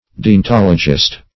Deontologist \De`on*tol"o*gist\, n. One versed in deontology.